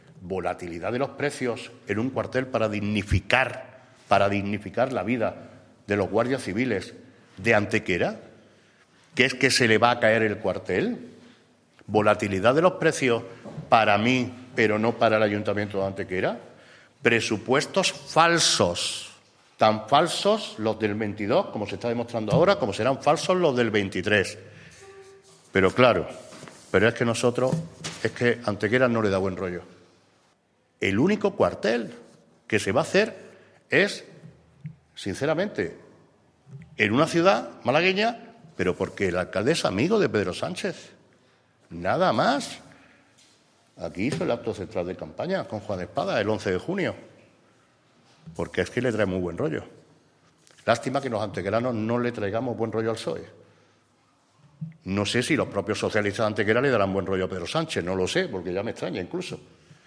El alcalde de Antequera, Manolo Barón, y el primer teniente de alcalde Juan Rosas han comparecido esta mañana ante los medios de comunicación para informar sobre la última hora del convenio, ya cerrado, que permitiría el desbloqueo del proyecto de construcción del nuevo acuartelamiento de la Guardia Civil en nuestra ciudad, en el aire desde el año 2008.
Cortes de voz